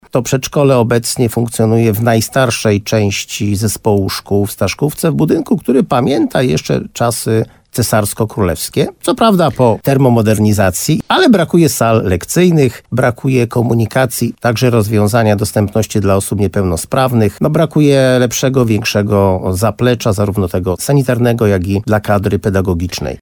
– Zmiana jest potrzebna, bo dzieci nie mieszczą się już w budynku – mówi wójt Jerzy Wałęga.